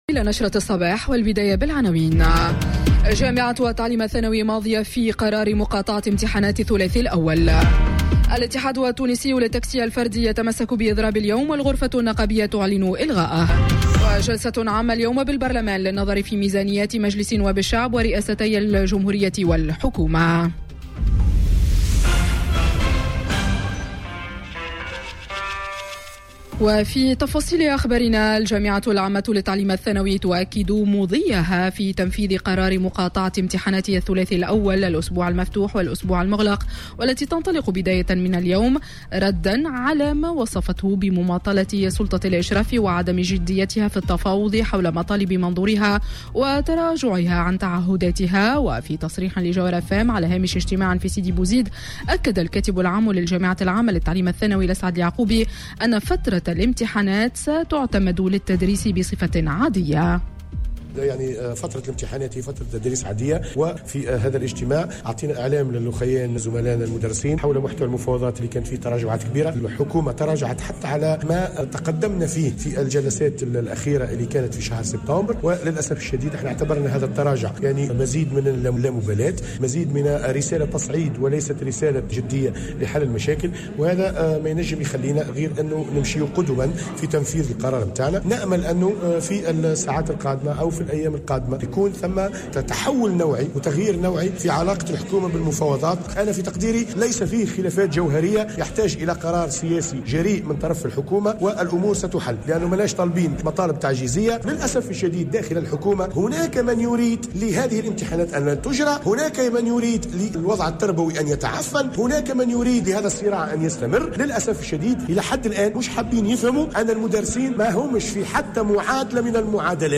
نشرة أخبار السابعة صباحا ليوم الإثنين 26 نوفمبر 2018